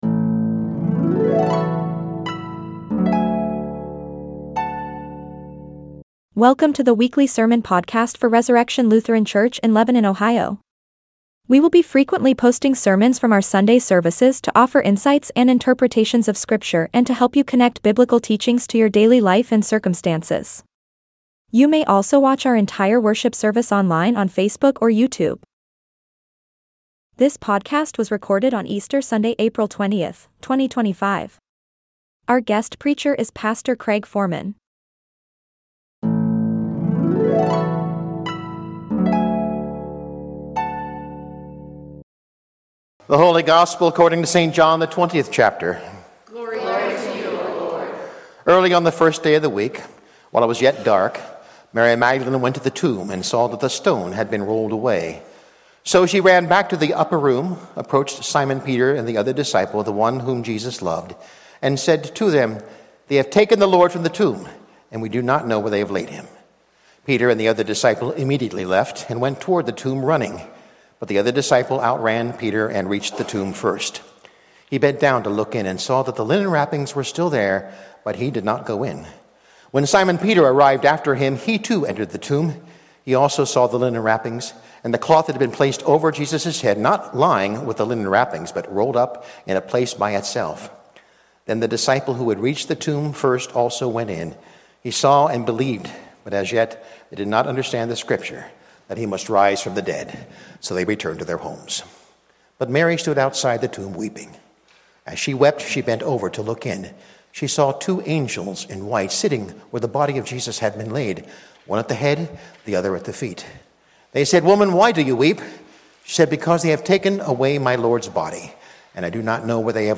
Sermons | Resurrection Lutheran Church